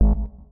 UIClick_Simple Button Synth 03.wav